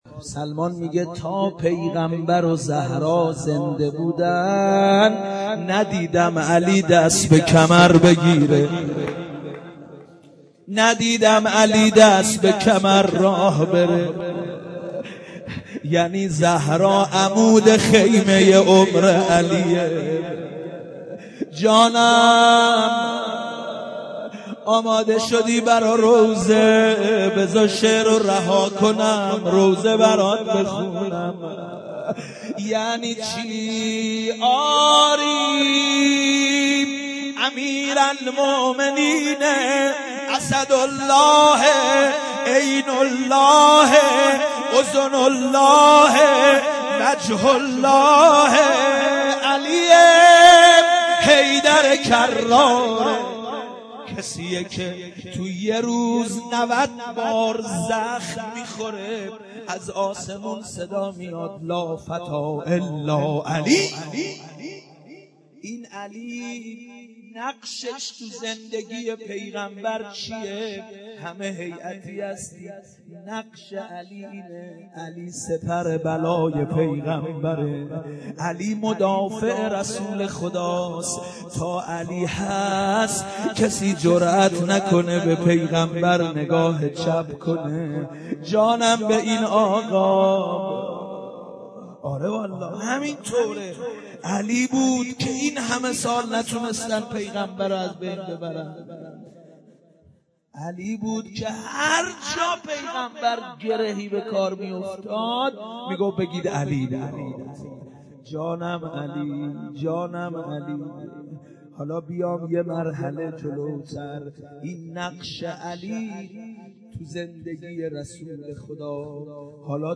دانلود مداحی بانوی ملک خدا - دانلود ریمیکس و آهنگ جدید
روضه زبانحال حضرت علی(ع